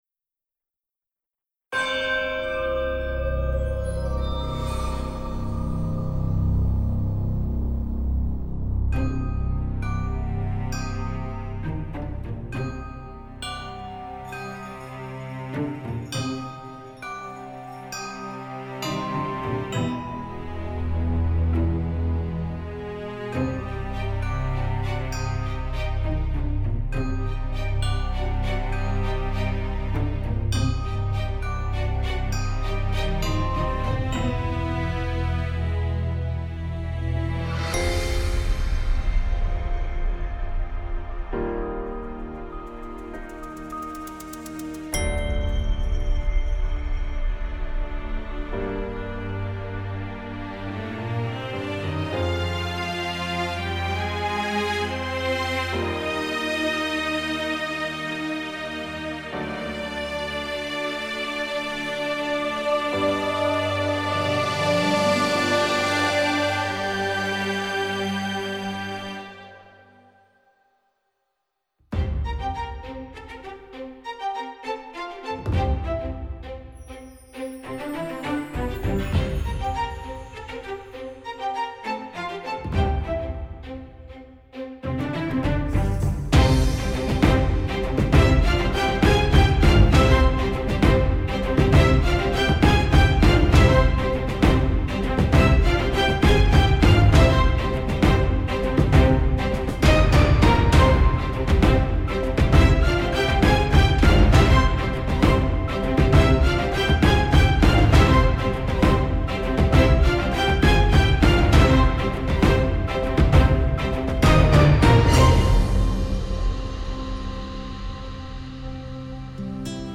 Musicgenre: POP INSTR.